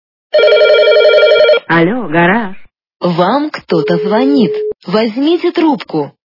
» Звуки » Смішні » Альо, гараж! - Вам кто-то звонит, возьмите трубку!
При прослушивании Альо, гараж! - Вам кто-то звонит, возьмите трубку! качество понижено и присутствуют гудки.